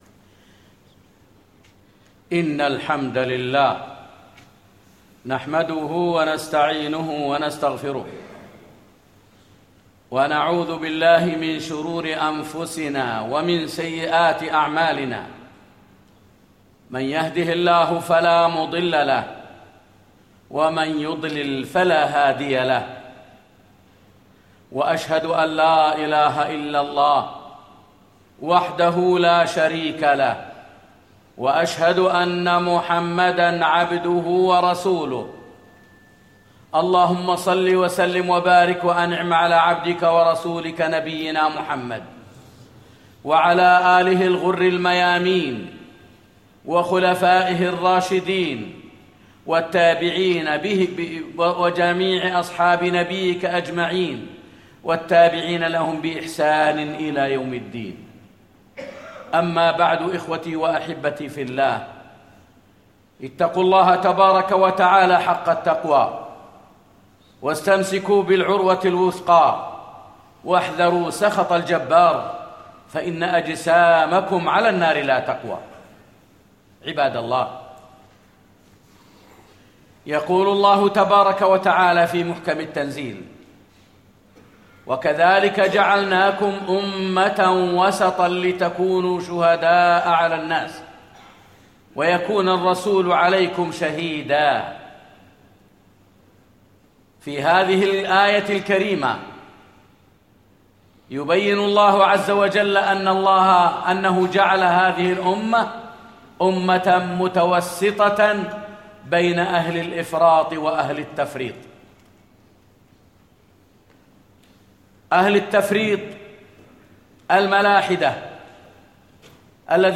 التحذير من الغلو والتطرف - خطبة